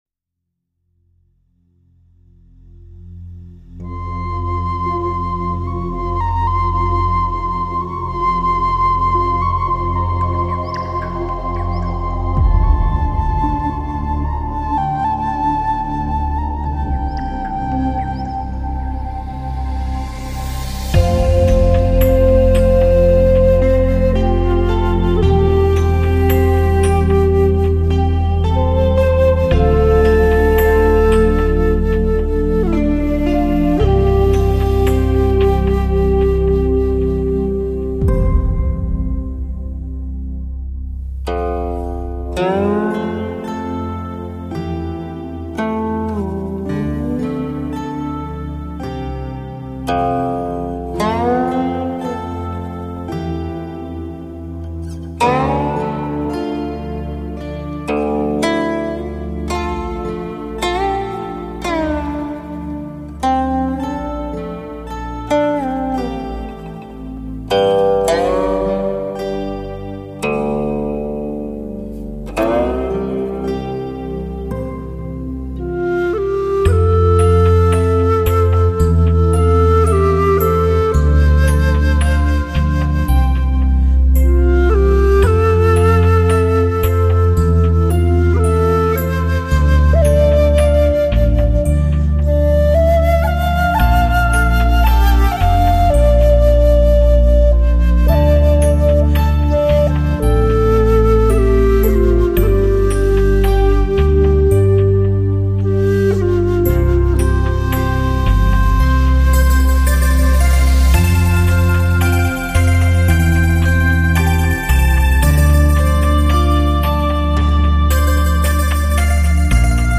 唱片类型：民族音乐
专辑语种：纯音乐
唱片介绍清越古朴的琴音轻轻流过身心，清微淡远冲淡岁月的沧桑，静谧神圣净化凡尘的浮躁，超然物外于清、空、虚、静的山水世界。